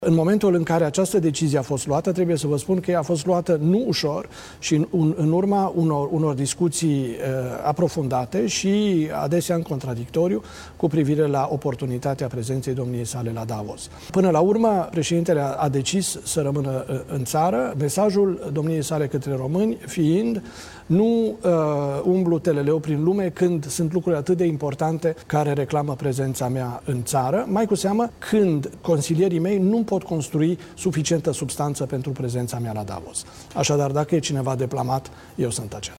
Nicușor Dan nu merge la Davos. Motivația a fost dată de consilierul prezidențial pentru securitate națională, Marius Lazurca: „Până la urmă, președintele a decis să rămână în țară”